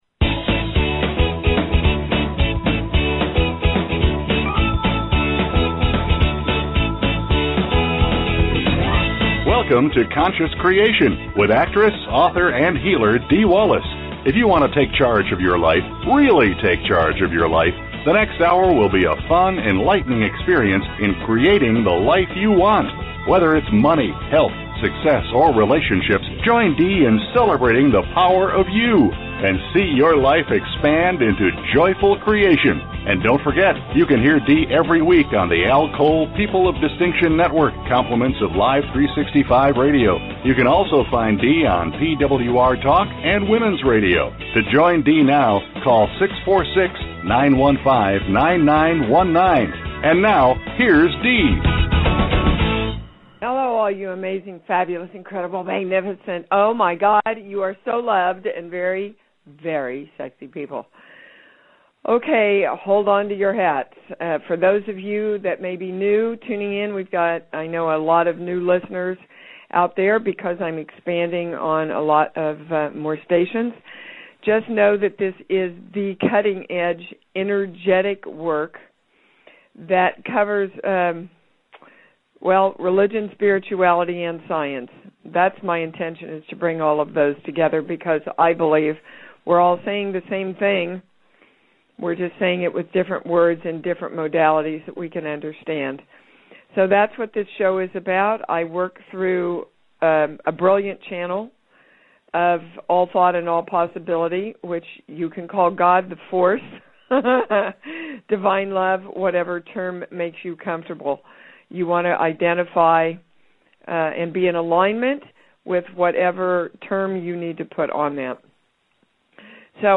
Talk Show Episode, Audio Podcast, Conscious Creation and with Dee Wallace on , show guests , about Core Truths,Balanced Life,Energy Shifts,Spirituality,Spiritual Archaeologist,Core Issues,Spiritual Memoir,Healing Words,Consciousness,Self Healing, categorized as Health & Lifestyle,Alternative Health,Energy Healing,Kids & Family,Philosophy,Psychology,Self Help,Spiritual,Psychic & Intuitive